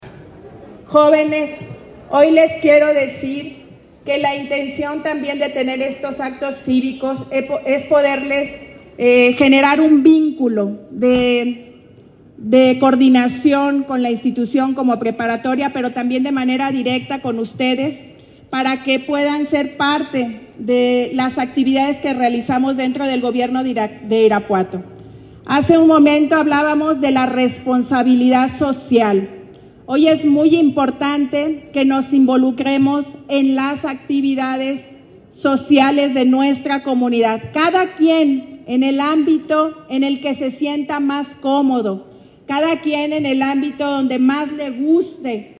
Irapuato, Gto. 18 de febrero del 2025 .- Para promover los valores cívicos, el Gobierno Municipal llevó a cabo los honores a la bandera con estudiantes y maestros de la Escuela de Nivel Medio Superior de Irapuato (ENMSI).
Lorena Alfaro García, Presindenta Municipal